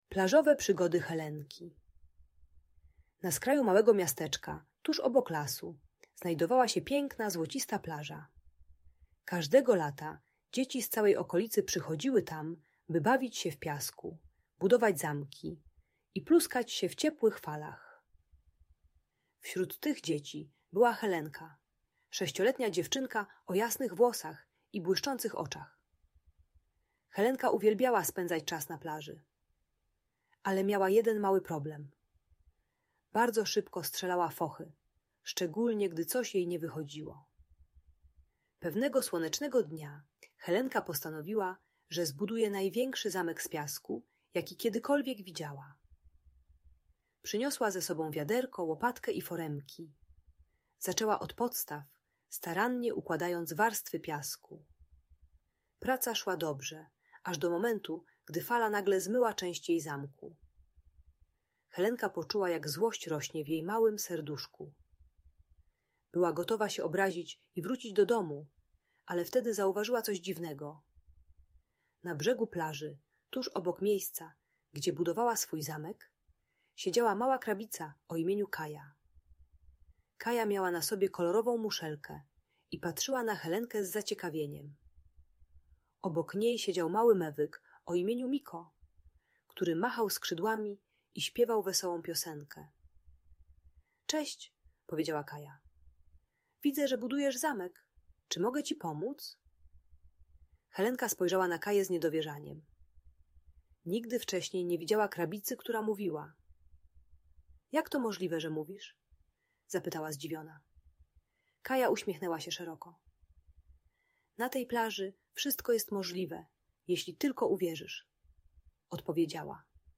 Plażowe Przygody Helenki - Audiobajka dla dzieci